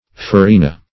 Farina \Fa*ri"na\ (f[.a]*r[imac]"n[.a] or f[.a]*r[=e]"n[.a]), n.